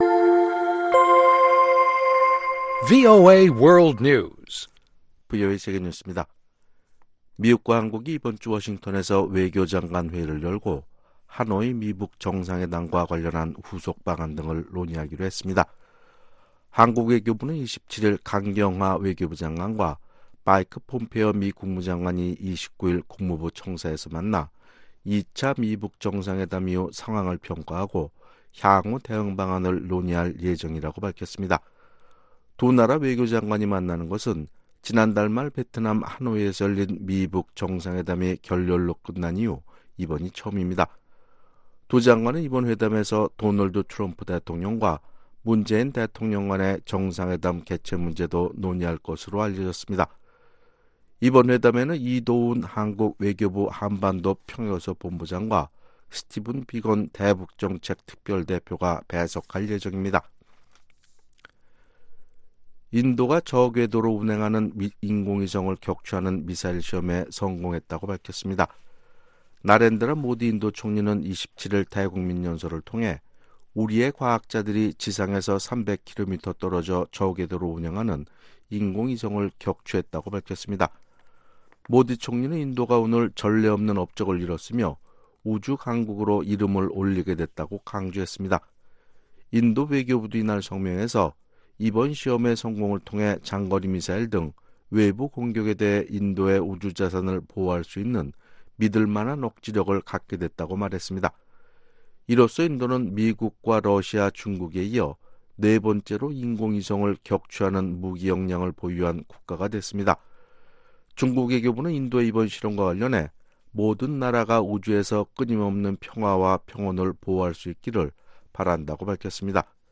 VOA 한국어 아침 뉴스 프로그램 '워싱턴 뉴스 광장' 2019년 3월 28일 방송입니다. 미 국무부는 최근 스페인 주재 북한 대사관 습격 사건의 미국 정부 개입설을 일축했습니다. 로버트 에이브럼스 주한 미군사령관은 북한과의 외교적 관여를 긍정적으로 평가하면서도 북한의 군사력을 여전한 위협으로 규정했습니다.